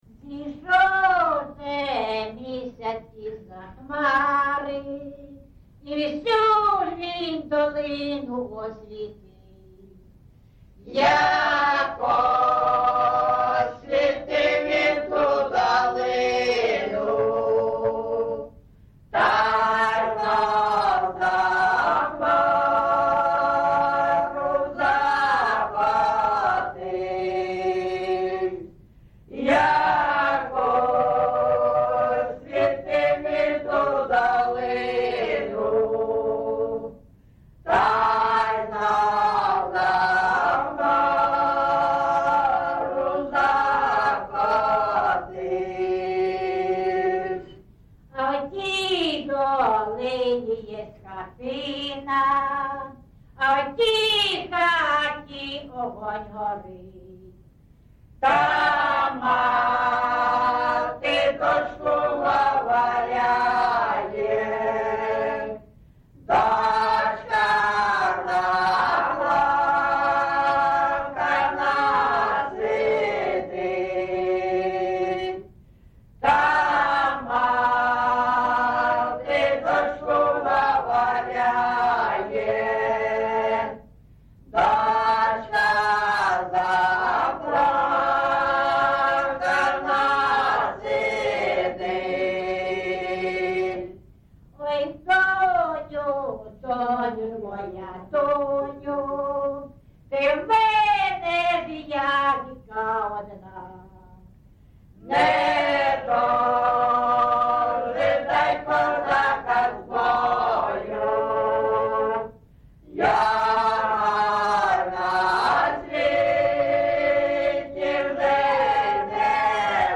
GenrePersonal and Family Life
Recording locationAndriivka, Velykа Novosilka district, Donetsk obl., Ukraine, Sloboda Ukraine